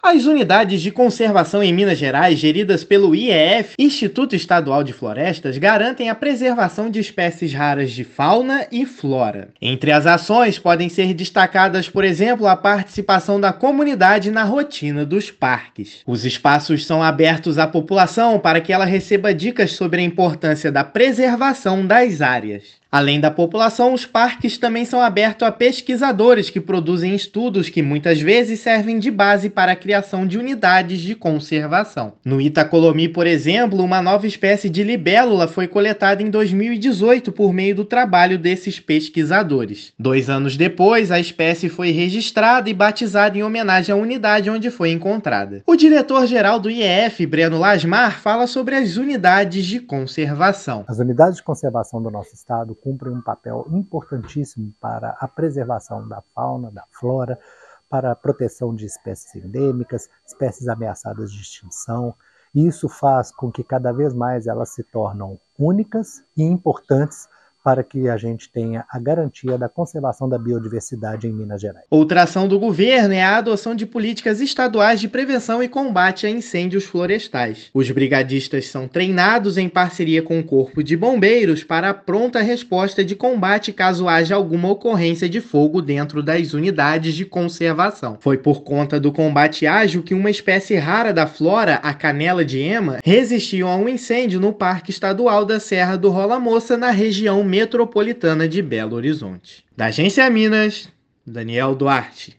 Espaços são geridos com a participação da população, que recebe dicas por meio de ações de educação ambiental; comunidade acadêmica também é protagonista na proteção das áreas. Ouça a matéria de rádio: